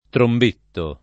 trombettare v.; trombetto [ tromb % tto ]